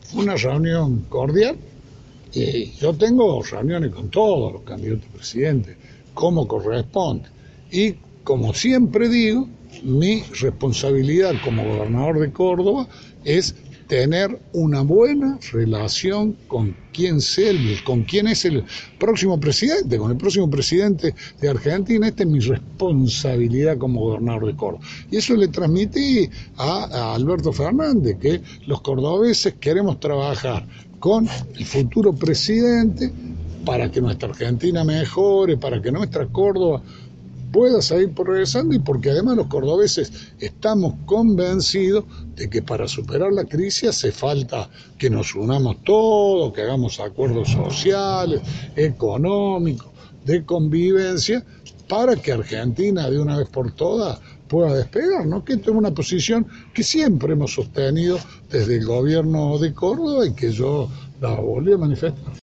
Una breve declaración de Schiaretti en Alta Gracia alcanzó fortalecer las versiones en los pasillos políticos de Córdoba sobre su acercamiento a Alberto Fernández.
Escucha las declaraciones de Schiaretti – Radiocyber (Carlos Paz)